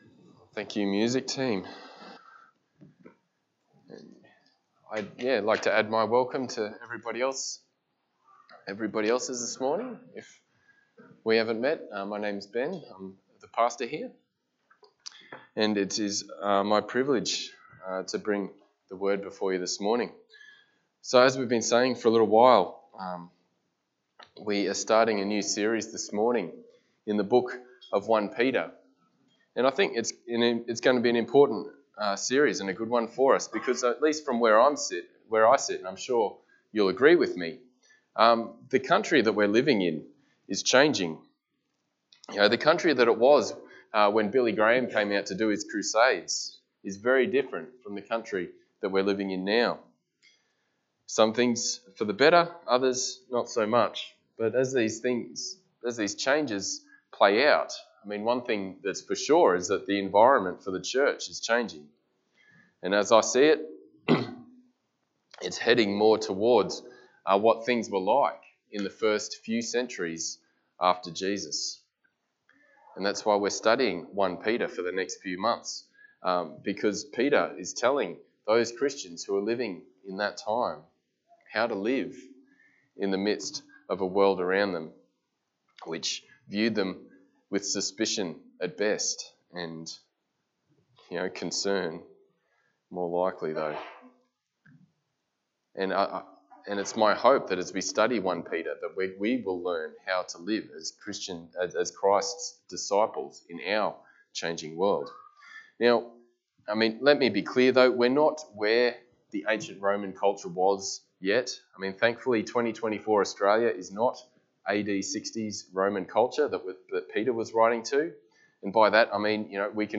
Passage: 1 Peter 1:1-2 Service Type: Sunday Morning